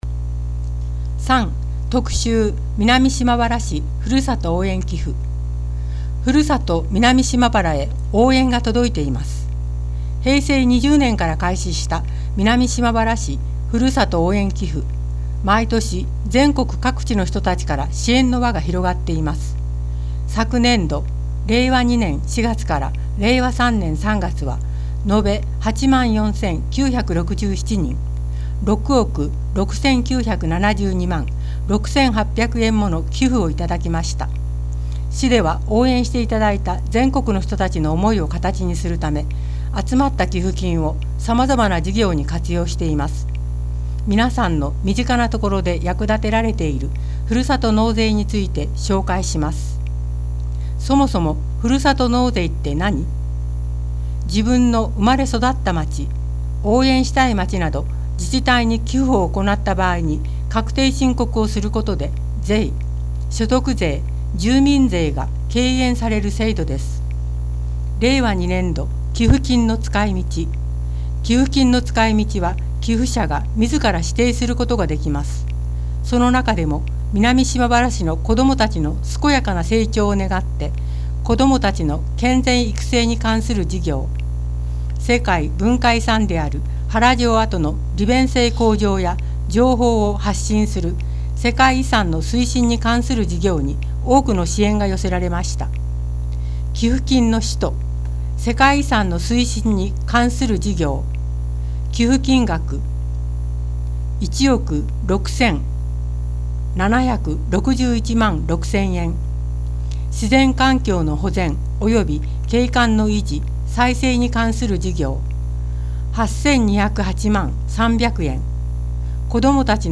音訳（※声の広報紙）
この音訳データは、音訳ボランティア「うぐいすの会」の皆さんにより作成された音読データをmp3形式で提供しています。